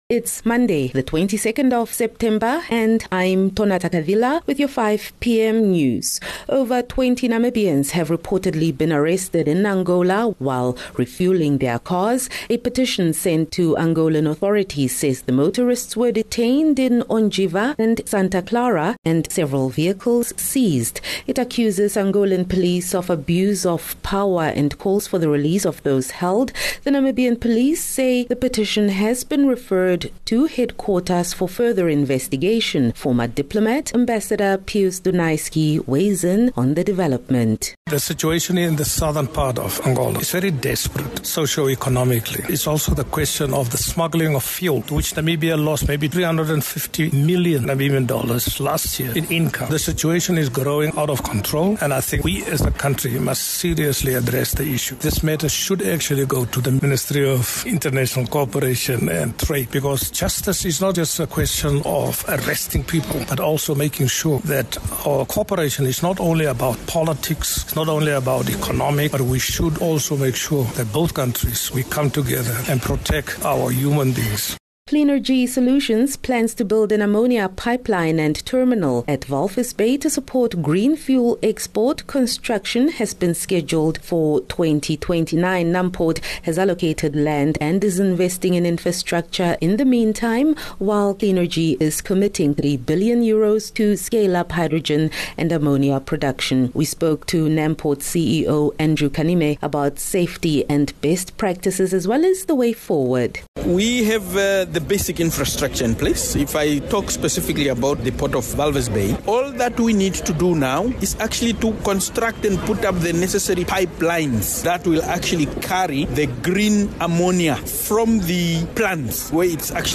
22 Sep 22 September - 5 pm news